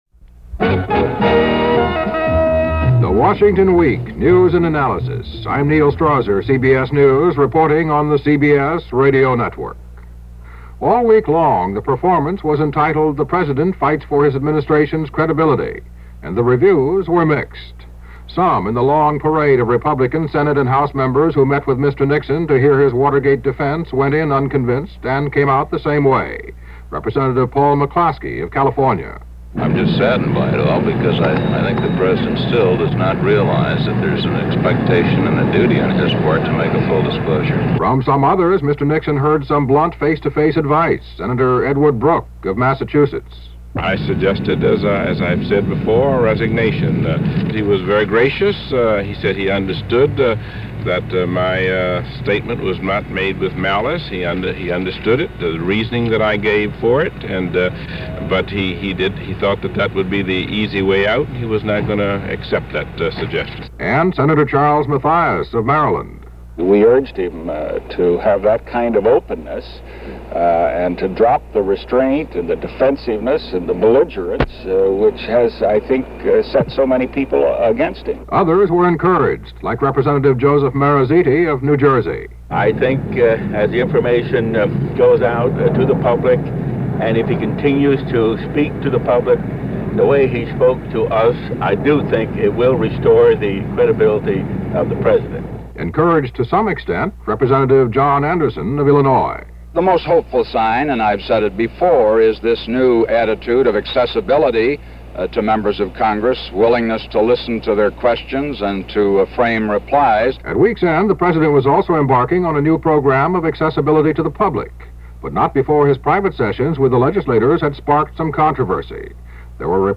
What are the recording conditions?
And that’s a small slice of what went happened in Washington, this week ending November 17, 1973 as presented by CBS Radio’s Washington Week.